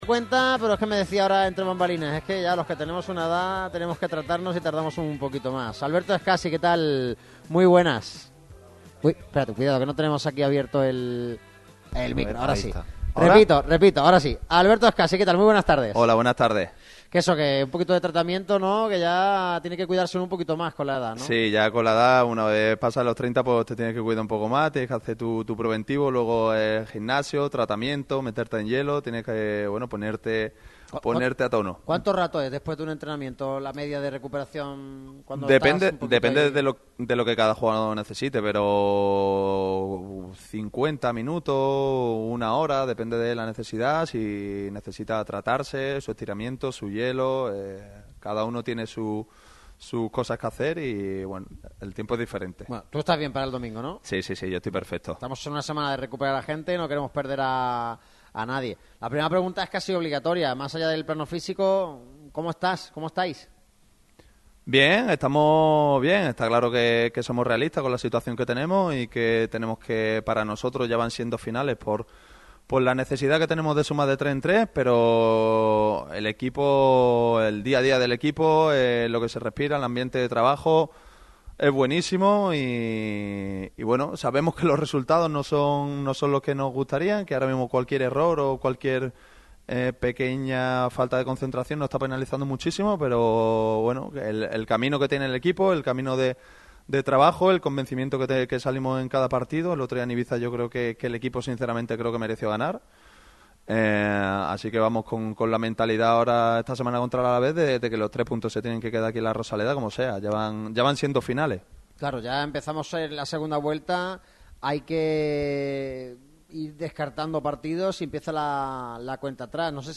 Alberto Escassi, capitán del Málaga CF, pasa por Radio Marca Málaga en la víspera del último compromiso de la primera vuelta.